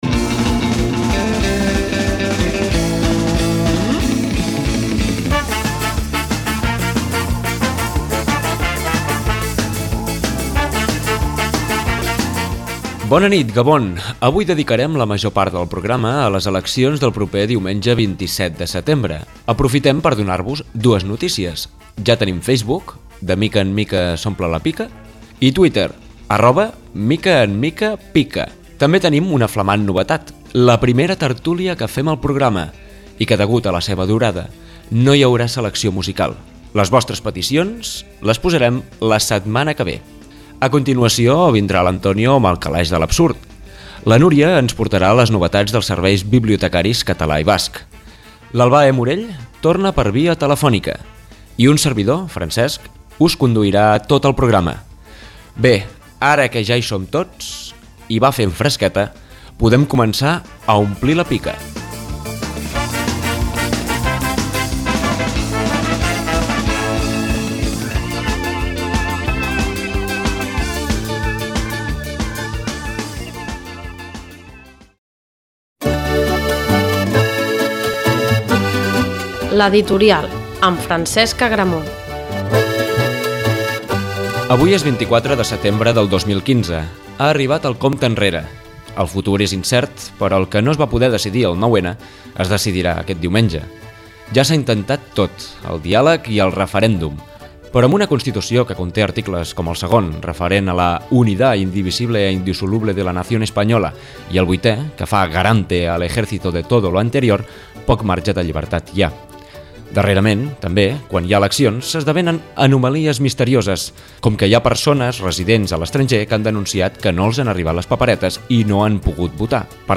A l’editorial, repassem la darrera setmana de la campanya electoral catalana; contraposant l’optimisme al discurs de la por i picant l’ullet a les ballarugues d’alguns candidats. A continuació, estrenarem la secció de tertúlia; aquest cop, política.